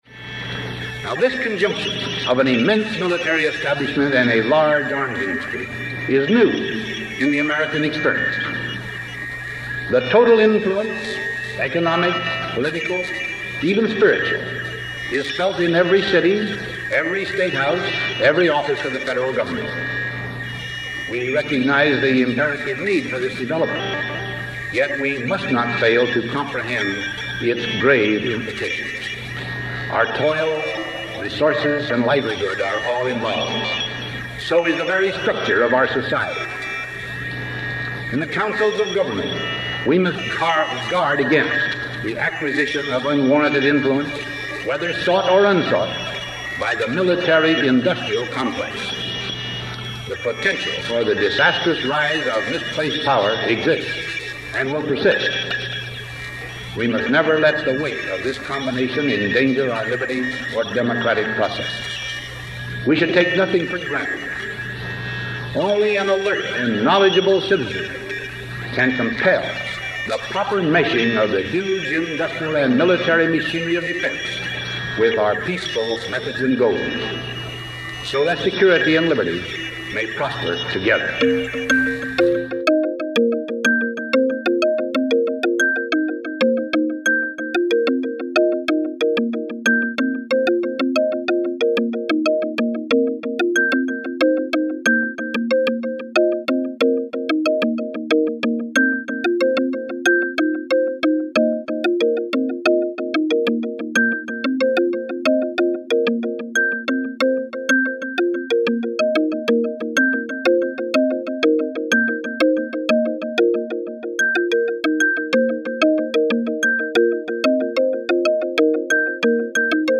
THE SPEECH
President of the United States and five-star general during World War II) Dwight D. Eisenhower used the term in his Farewell Address to the Nation on January 17, 1961: